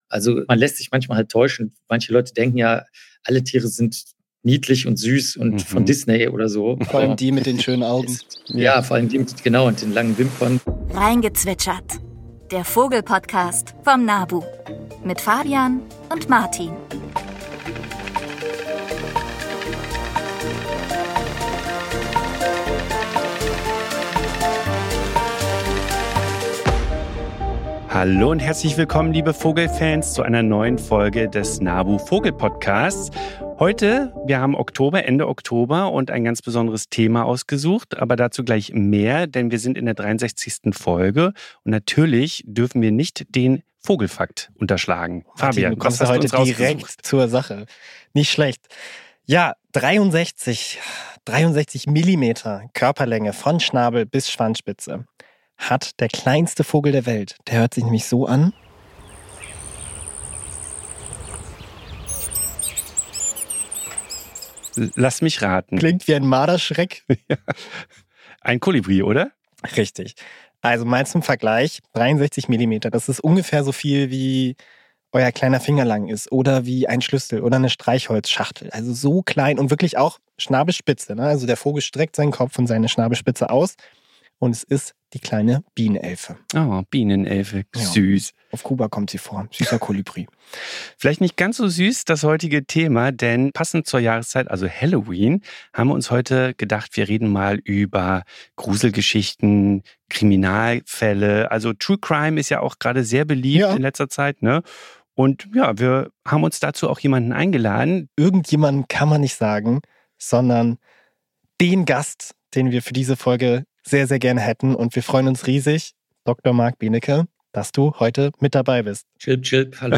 Zwischen Forensik, Vogelgezwitscher und schrägem Humor klären wir die wichtigsten Fragen rund um Mord, Möwen und Meisen.